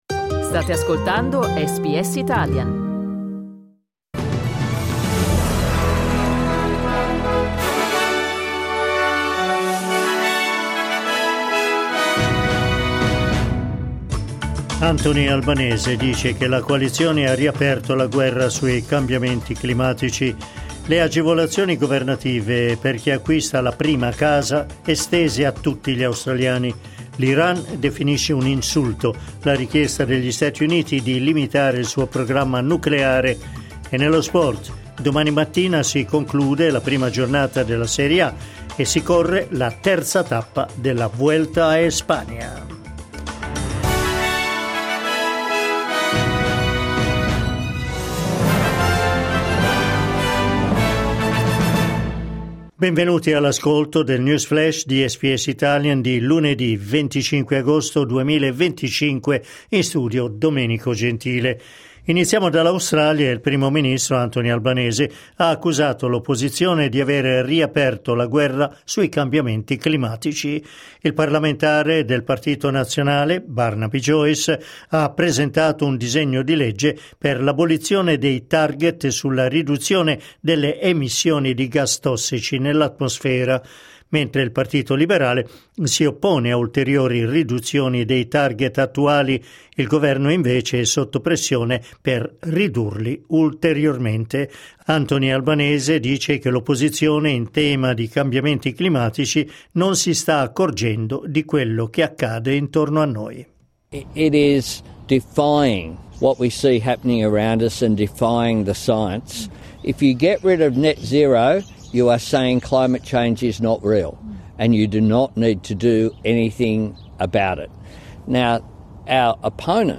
News flash lunedì 25 agosto 2025
L’aggiornamento delle notizie di SBS Italian.